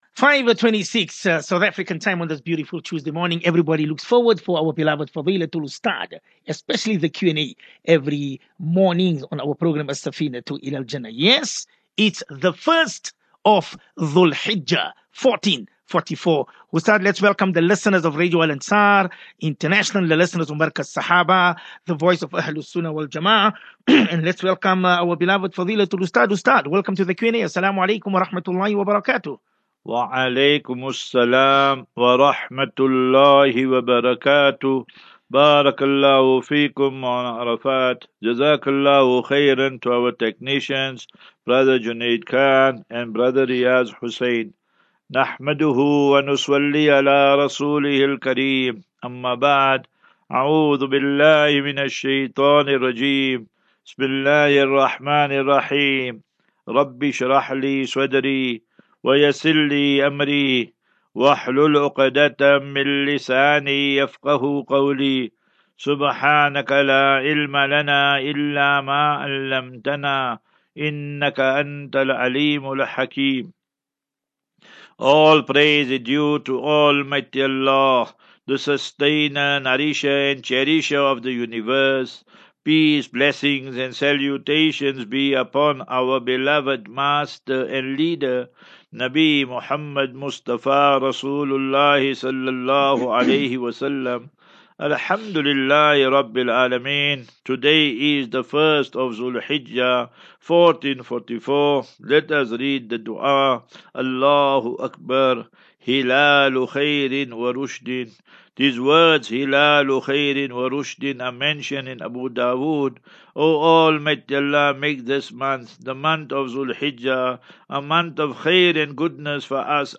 Daily Naseeha.